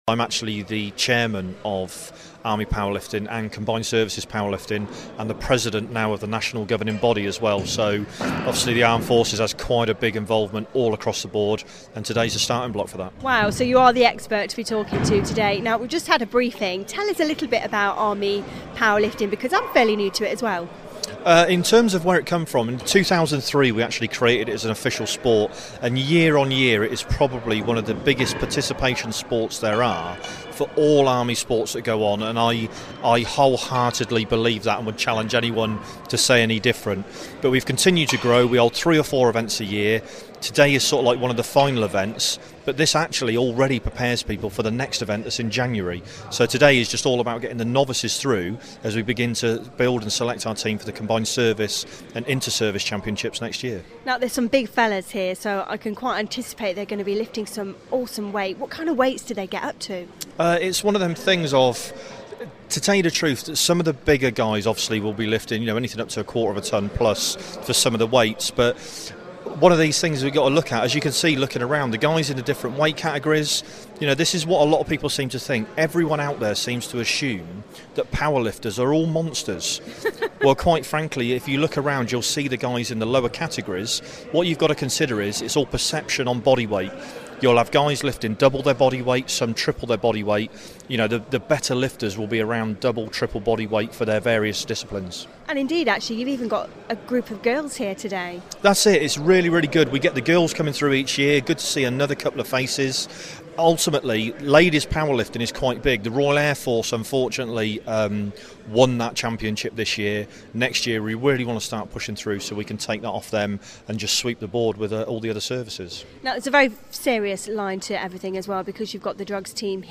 at the Army Powerlifting Championships